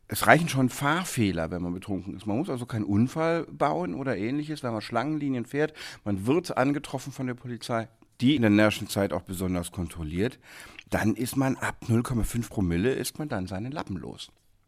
O-Ton: Alkohol und Führerschein
O-Töne / Radiobeiträge, , , , , ,